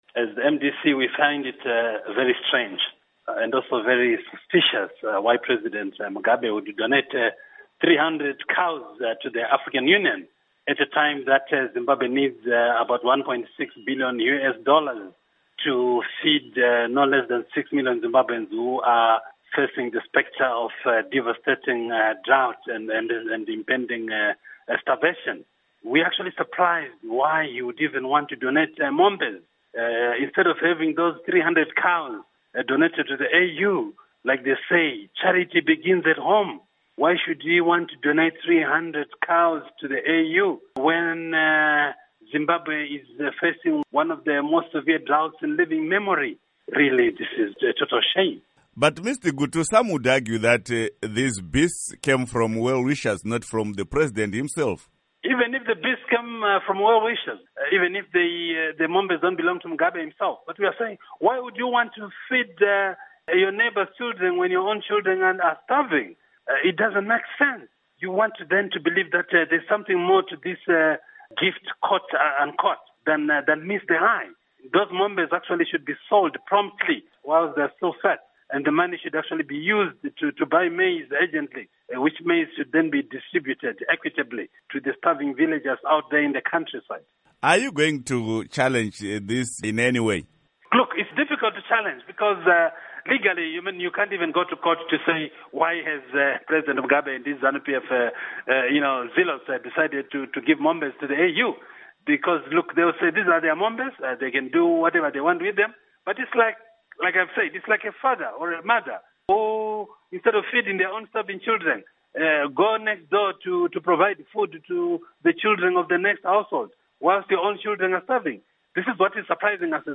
Interview With Obert Gutu